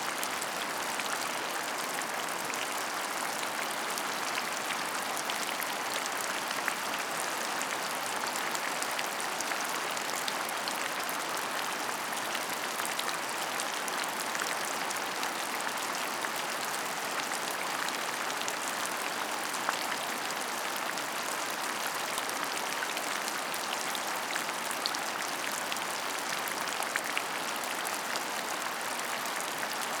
rain_loop.wav